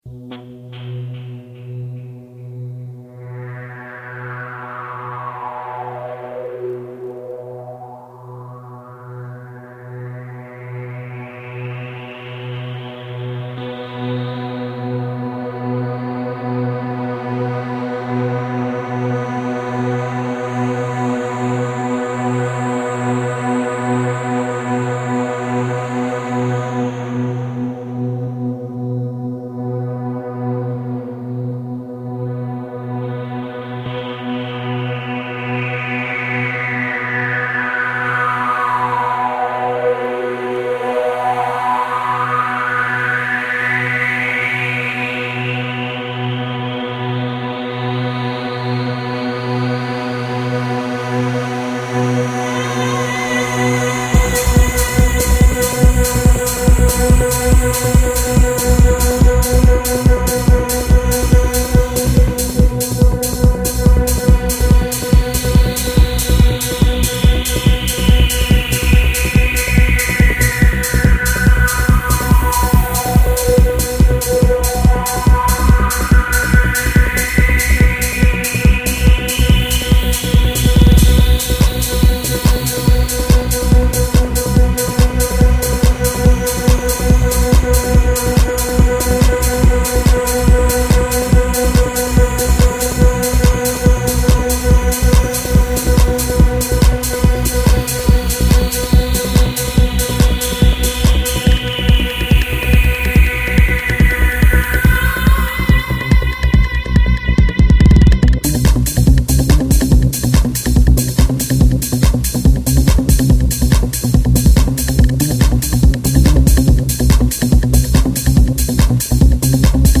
Style: Psy-Trance, Goa